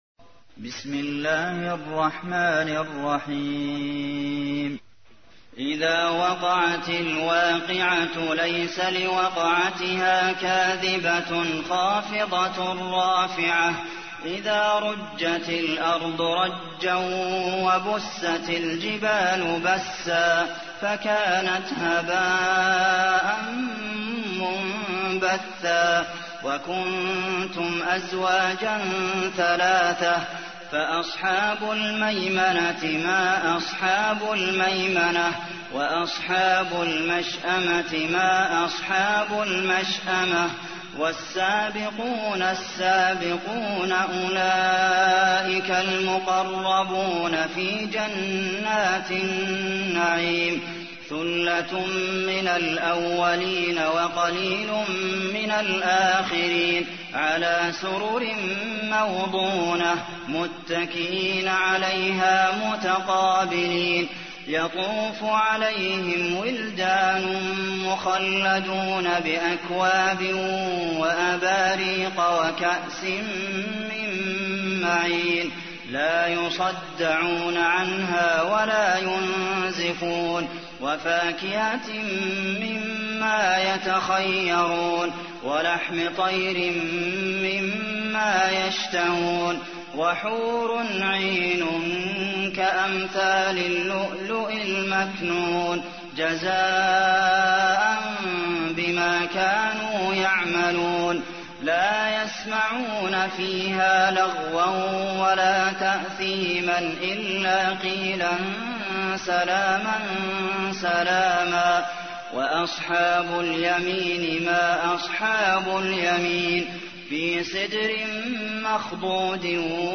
تحميل : 56. سورة الواقعة / القارئ عبد المحسن قاسم / القرآن الكريم / موقع يا حسين